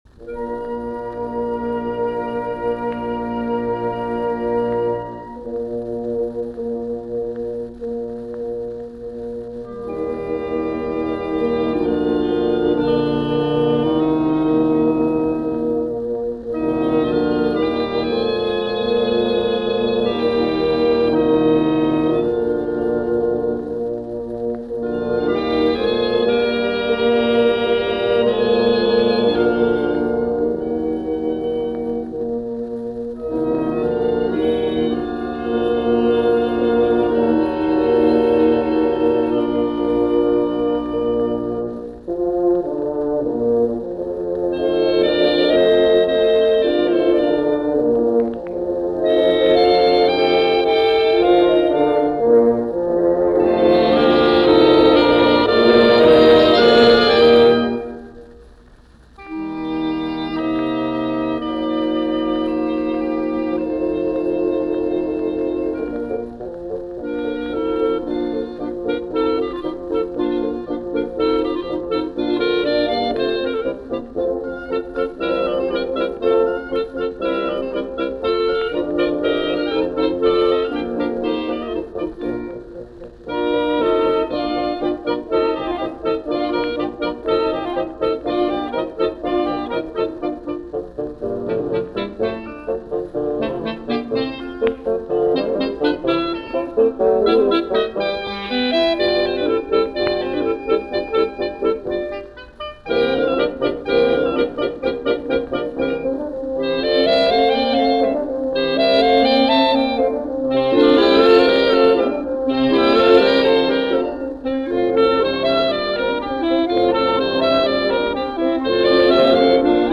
Charles Gounod: Petite Symphonie for Winds – Student Orchestra of the Paris Conservatory – Fernand Oubradous, cond. – 1952 Broadcast performance
Its instrumentation is simply harmonie ensemble plus a single flute, reflecting both the somewhat backward-looking philosophy of the Societé and the importance of Taffanel.
The first movement has a slow introduction followed by an allegro in sonata form.